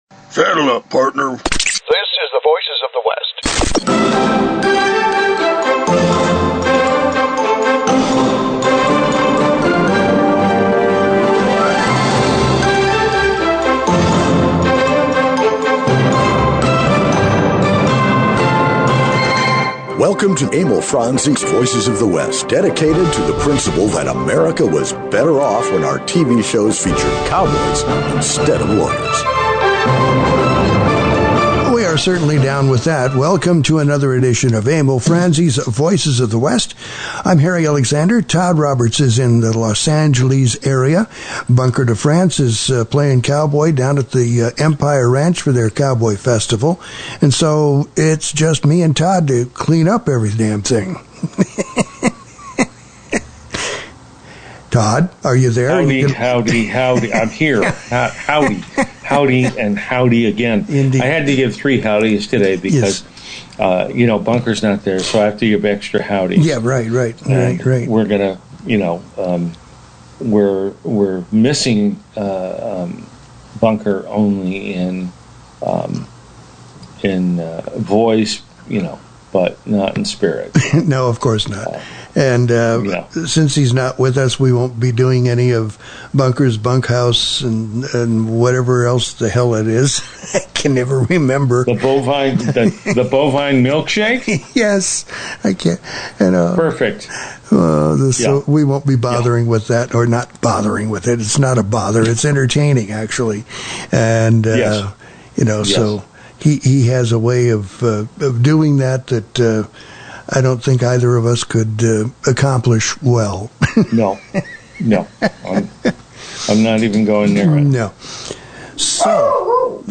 The show went to Wilcox for the annual Wild Bunch Film Festival.